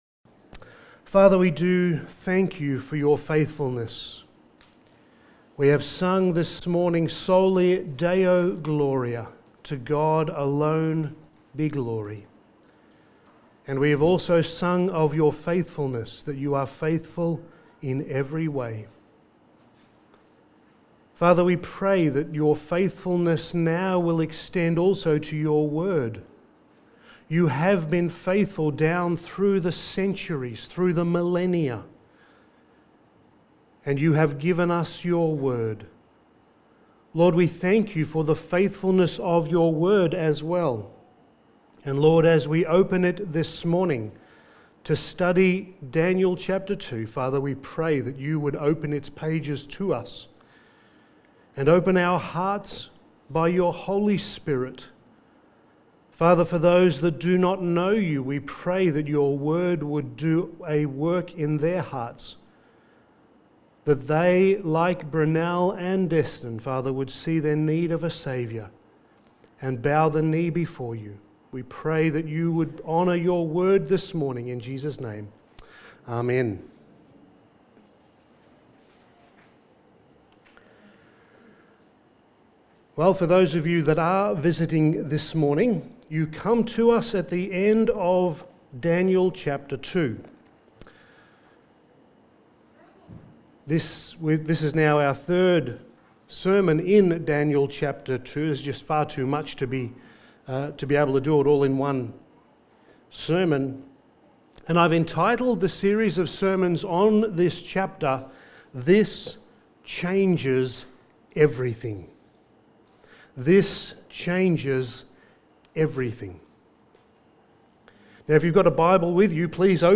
Passage: Daniel 2:36-49 Service Type: Sunday Morning